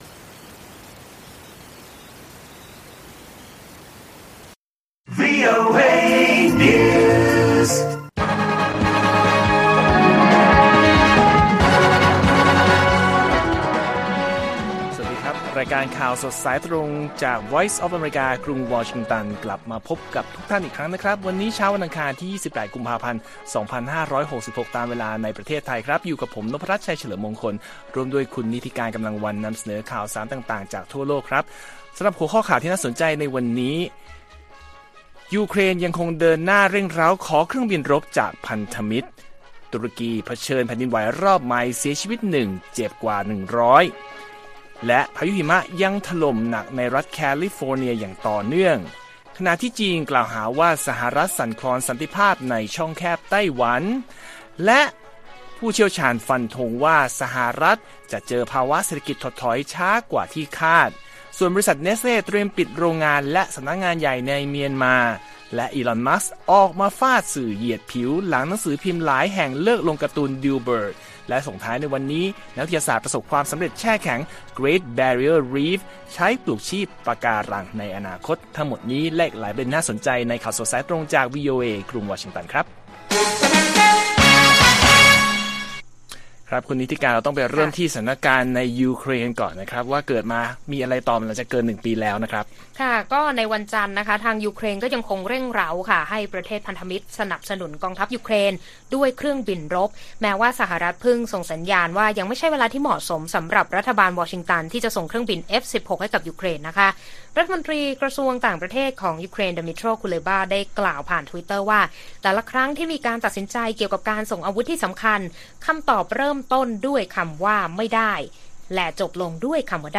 ข่าวสดสายตรงจากวีโอเอไทย 6:30 – 7:00 น. 28 ก.พ. 2566